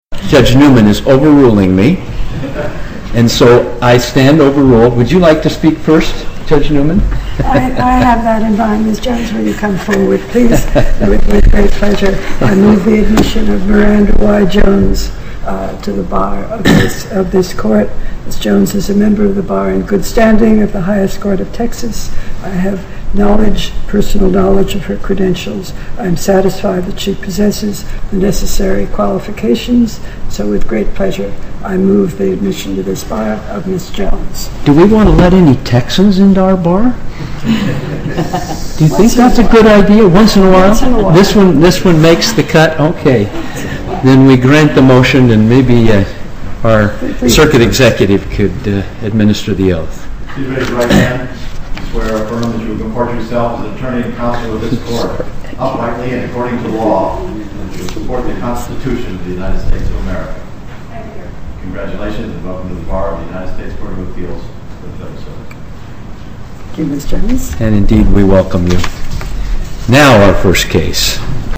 I’m not sure Chief Judge Rader will be invited back to Texas after this recent swearing-in ceremony.